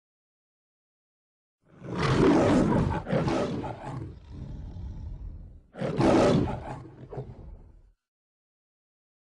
Aslan Kükremesi